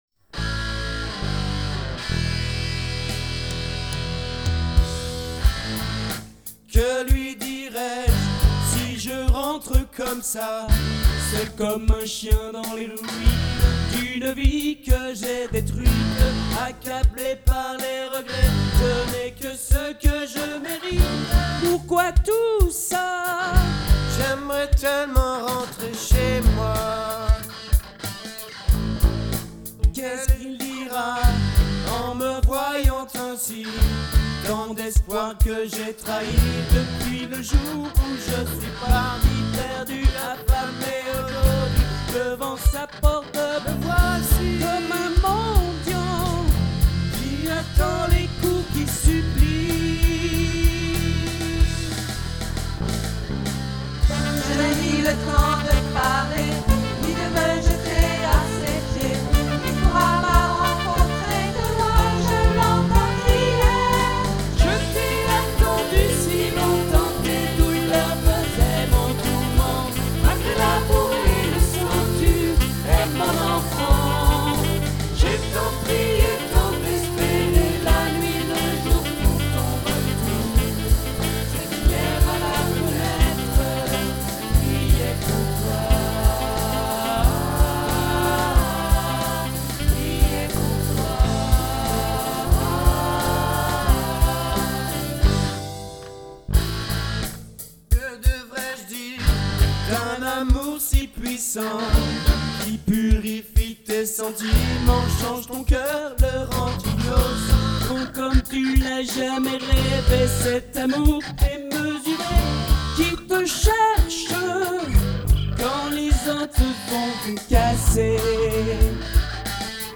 Enregistré lors du week-end unison